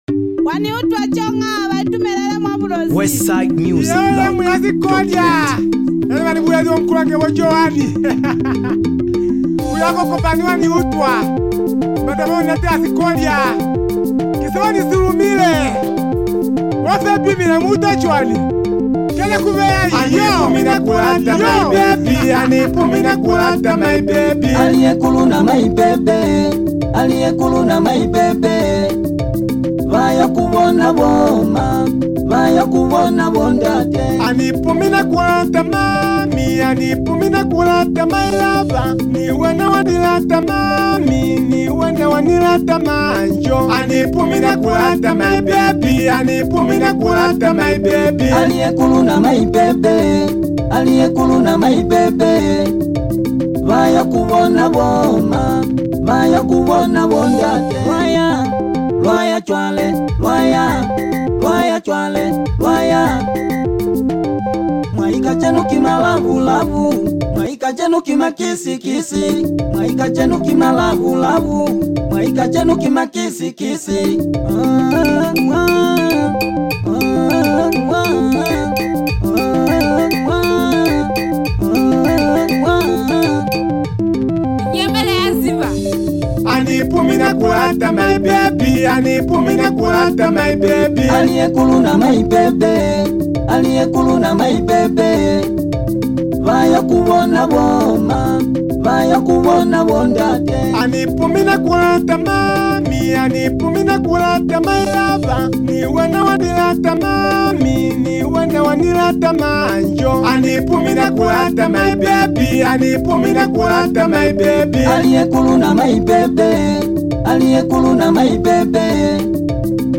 Genre: Afro-beats, Zambia Songs